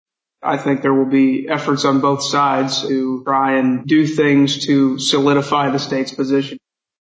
Missouri Senate Majority Floor Leader Caleb Rowden of Columbia expects the Legislature to build on the ruling: